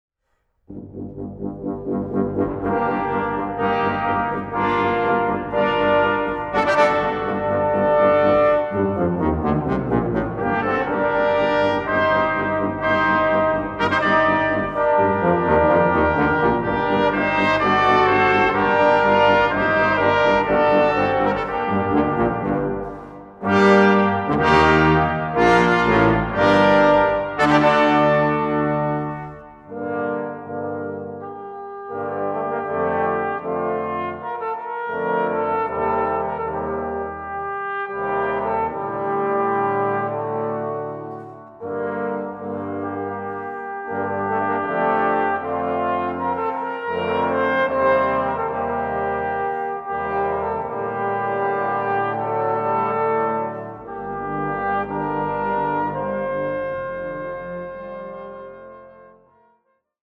Aufnahme: Jesus-Christus-Kirche Berlin-Dahlem, 2011